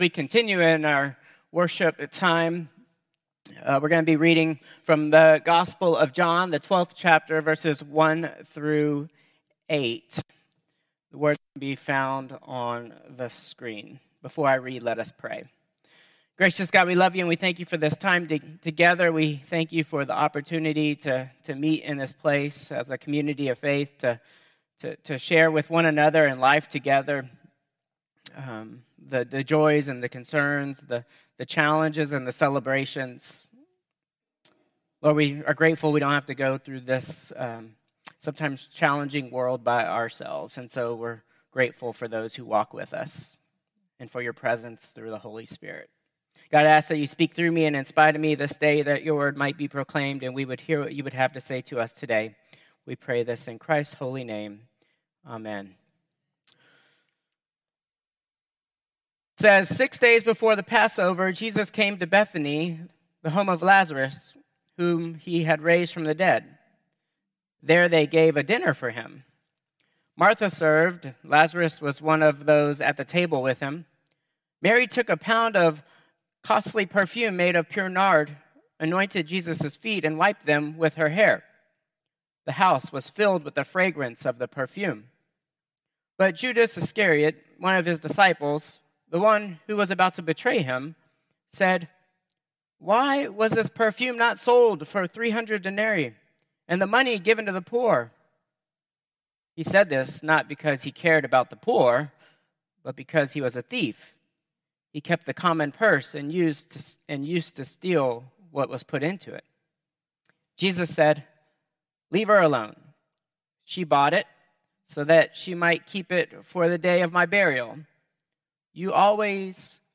Contemporary Service 4/6/2025